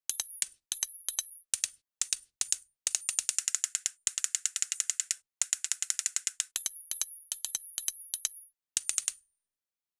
chaching 0:10 Efecto de sonido corto 0.5 a 1s para recoleccion de dinero en juego tycoon. Brillante, positivo y satisfactorio, como monedas cayendo o un chaching. Debe sonar claro, agudo y alegre, repetible sin cansar, transmitiendo recompensa inmediata. 0:02 Efecto de sonido corto 0.5 a 1s para recolectar dinero en juego tycoon.